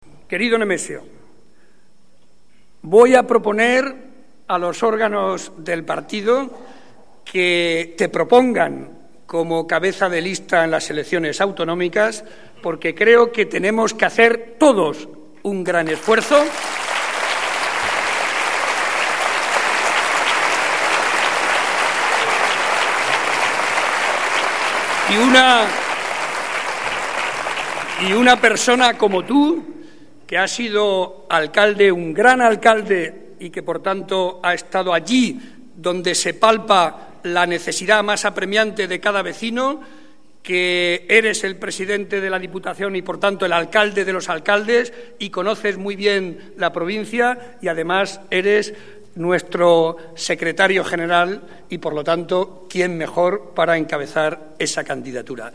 En el acto de celebración del centenario de la Agrupación de Puertollano.
Cortes de audio de la rueda de prensa